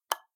menuhit.ogg